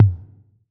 6TOM LW 1.wav